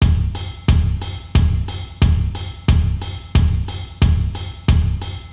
Tempo : 90
Il suffit de maîtriser le jeu en contre-temps. Le batteur utilise aussi la semi-ouverture charley pour plus de punch.